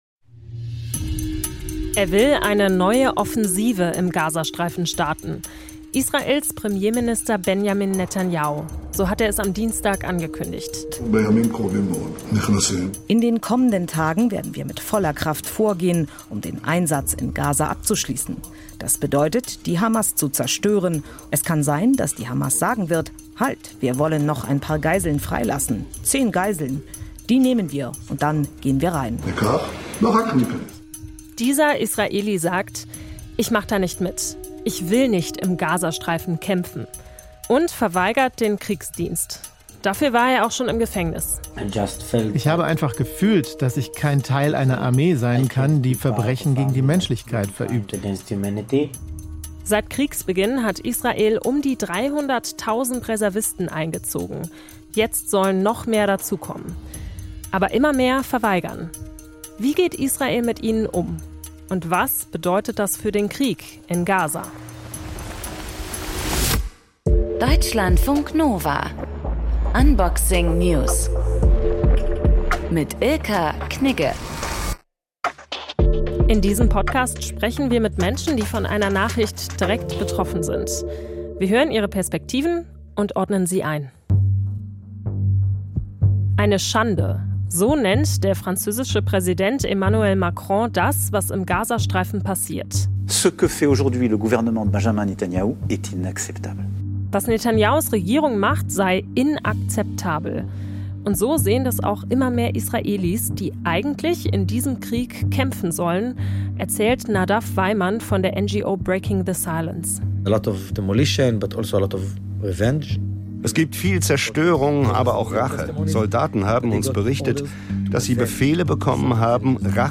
Der Wortwechsel ist das Diskussionsforum bei Deutschlandfunk Kultur – mit interessanten...